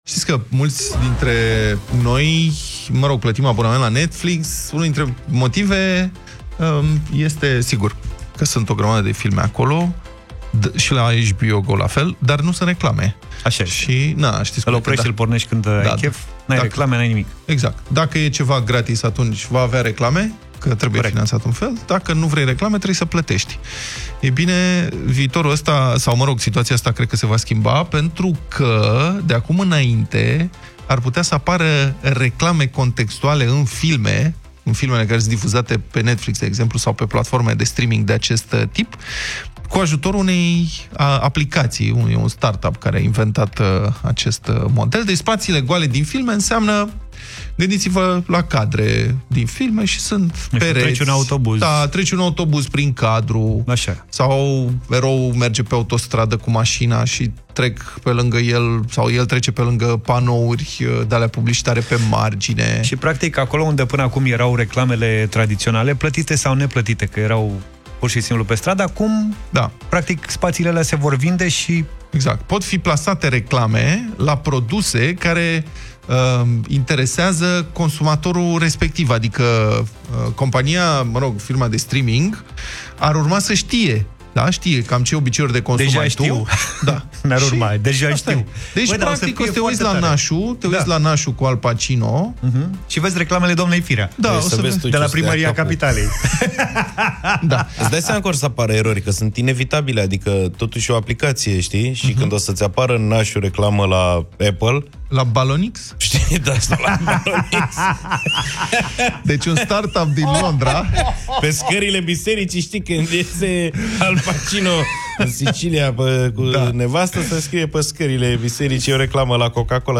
au vorbit despre acest subiect în această dimineață în Deșteptarea.